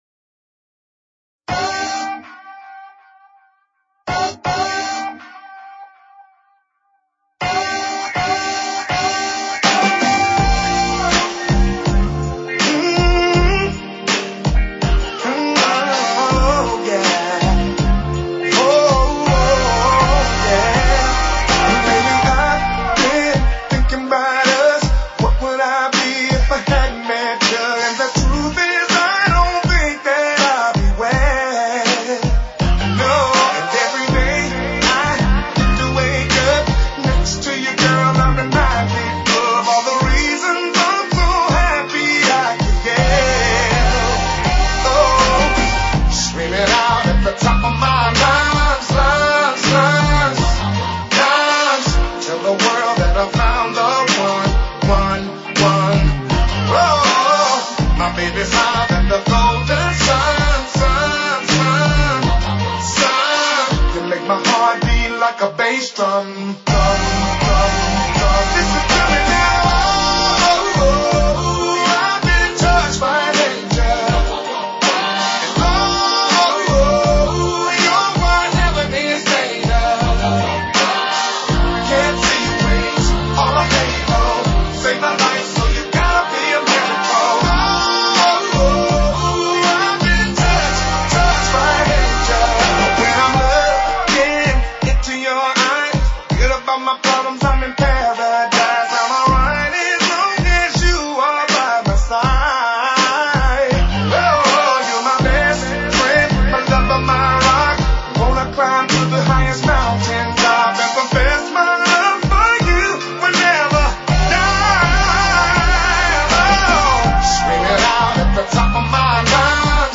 Rhythm and Soul